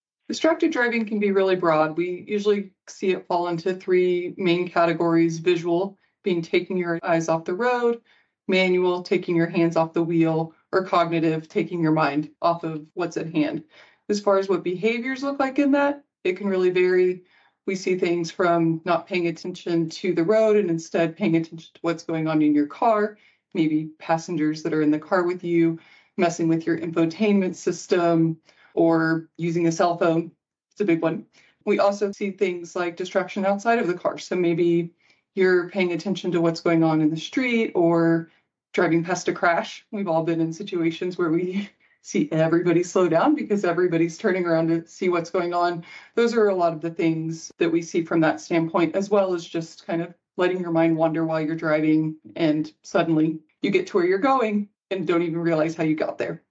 Engaging conversations about transportation innovations
eyes-on-the-road-encouraging-behaviors-to-reduce-distracted-driving-soundbite.mp3